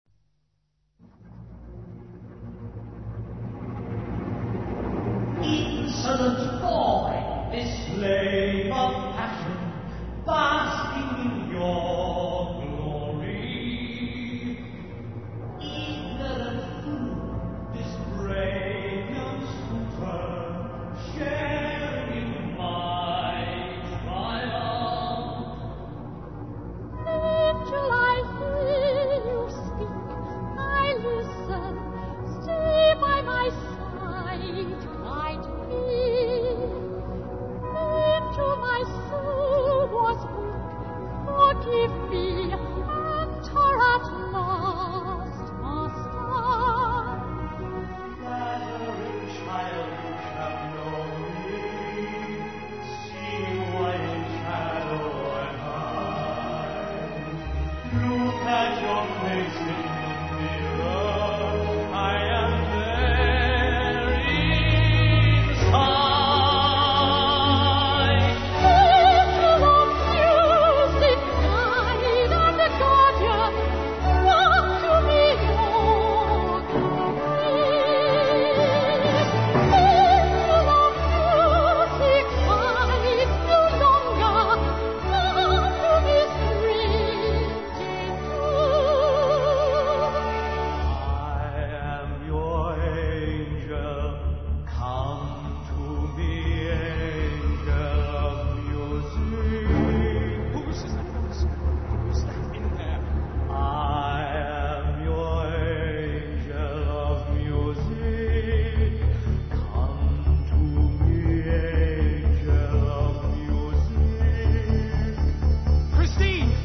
( Tremulous music.